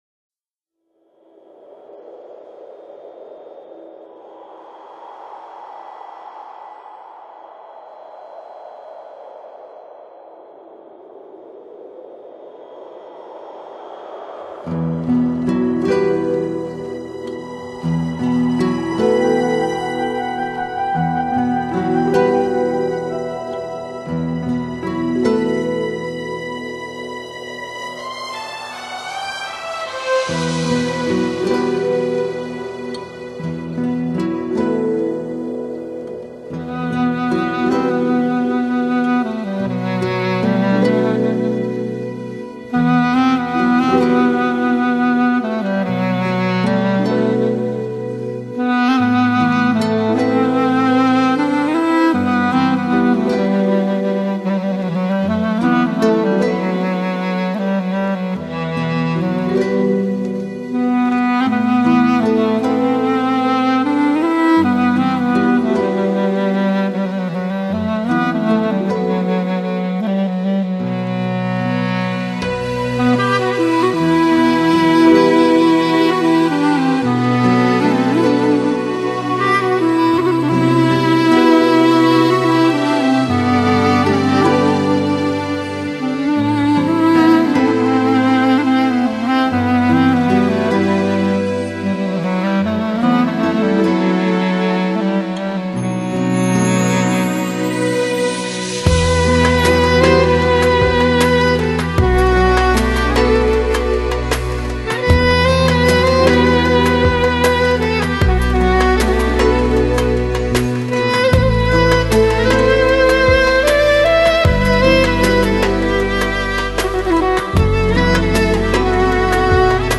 Country: Turkey